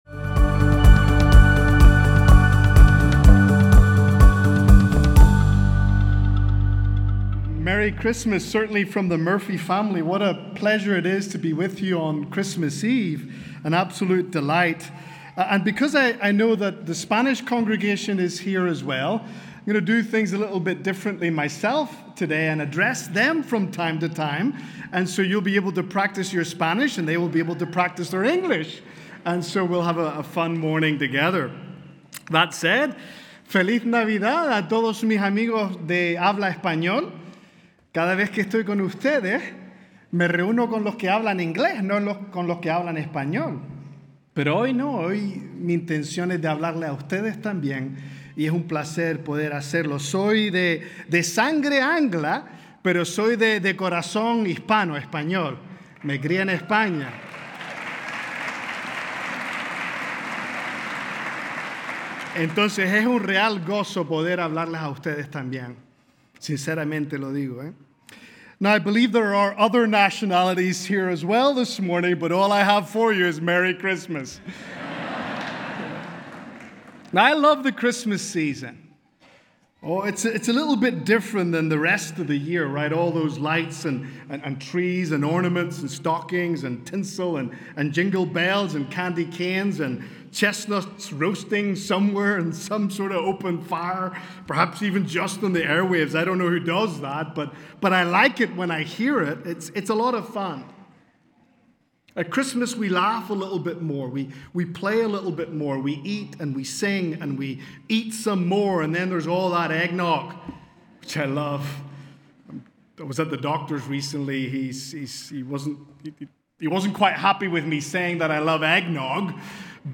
Celebrate our Savior’s birth this Christmas Eve with a joyful morning of worship.
Listen to Message